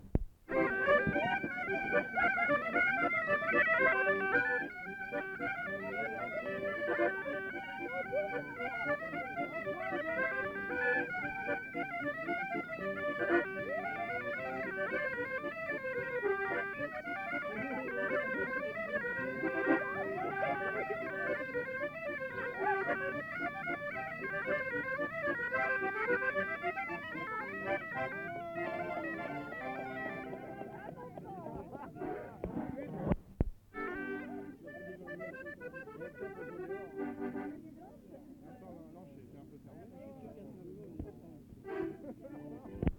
Bourrée